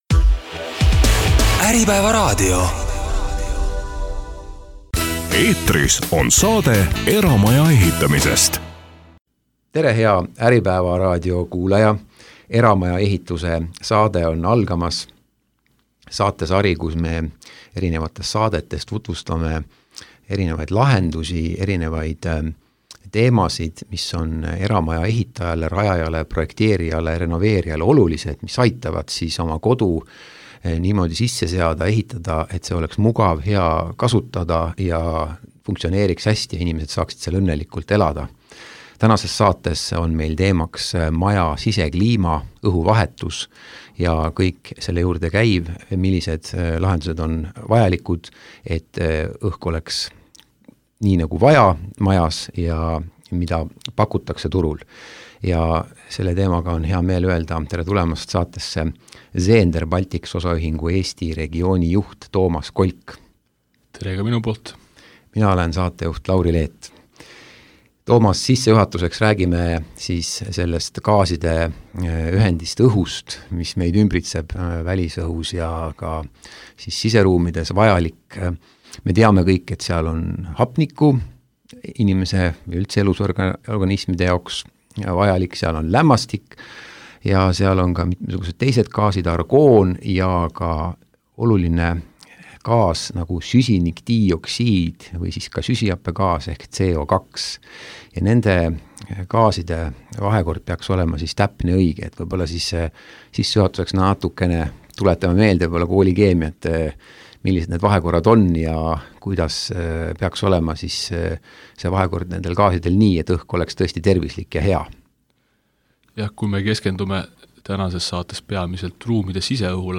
Äripäeva raadios käis Zehnderi tervislikke sisekliima lahendusi tutvustamas